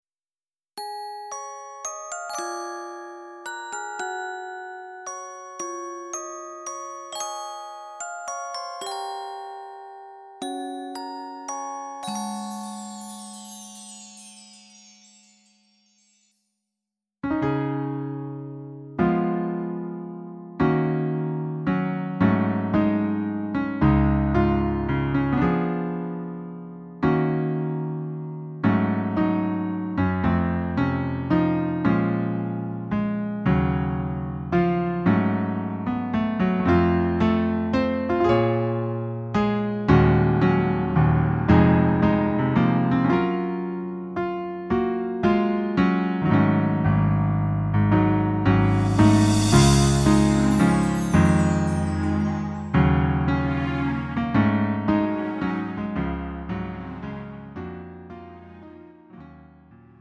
음정 -1키 3:21
장르 가요 구분 Lite MR